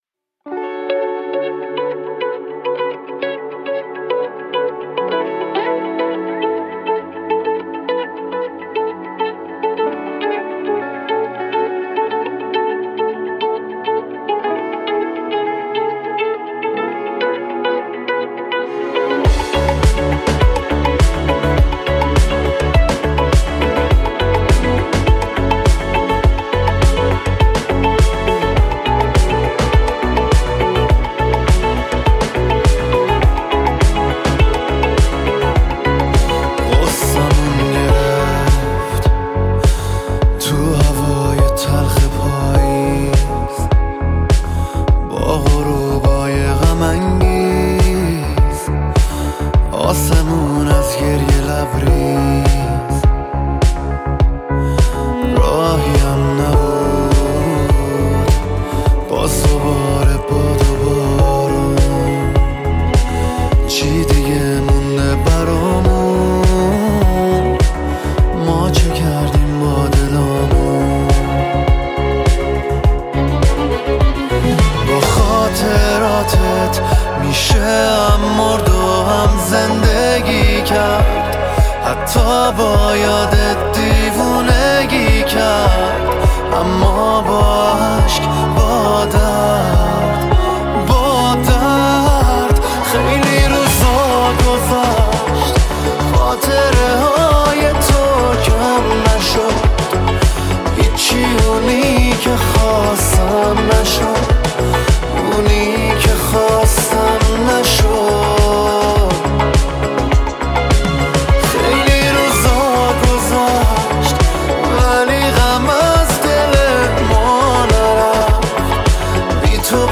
ویولون
گیتار
تک اهنگ ایرانی